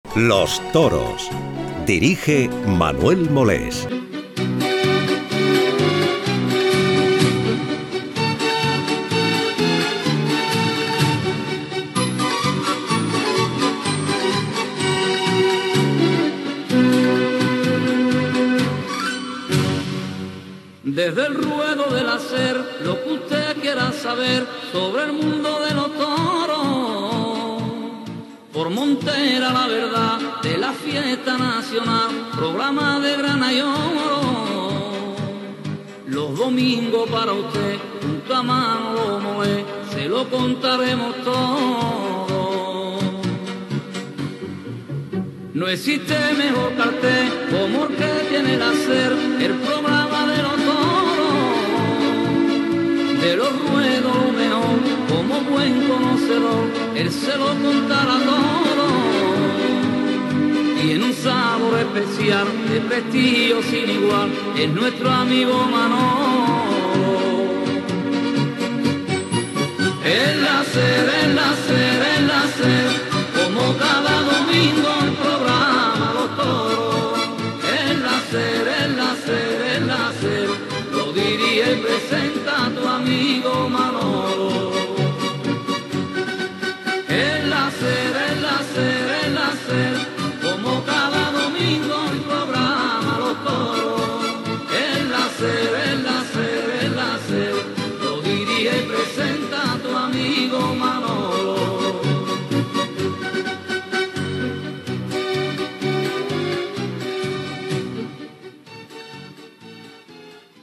Identificació i sintonia cantada del programa
Programa presentat per Manolo Molés.